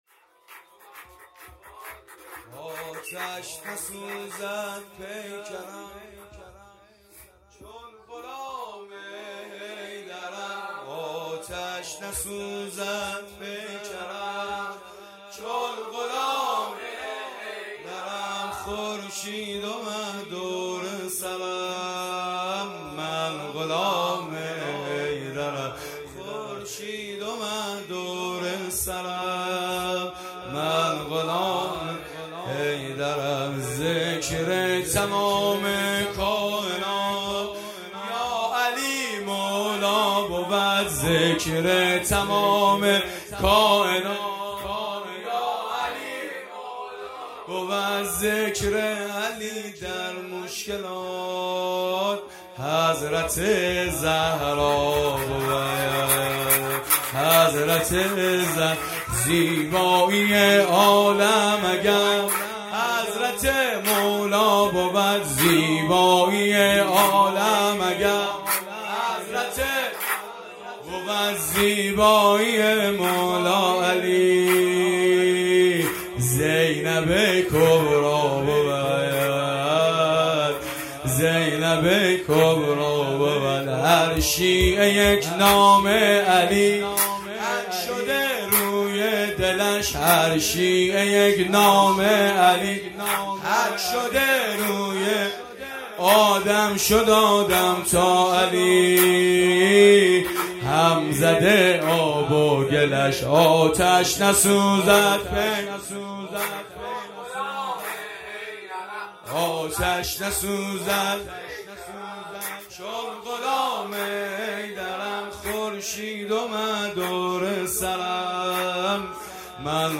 ایام نیمه شعبان